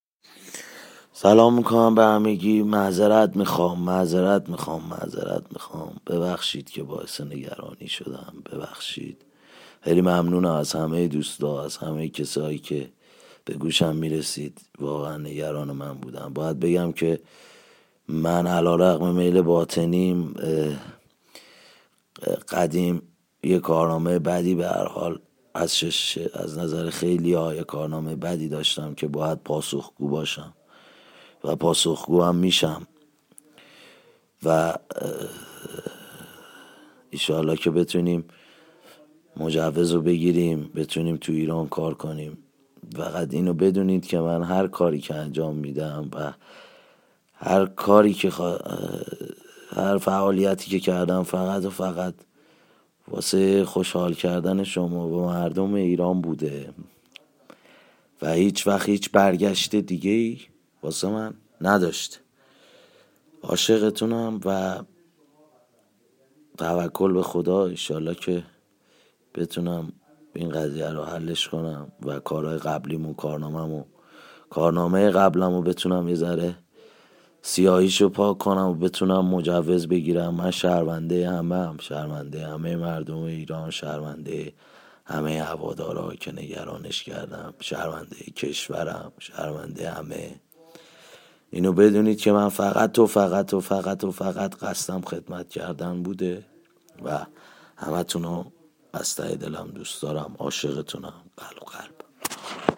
صوت/پيام صوتي امير تتلو پس از آزادي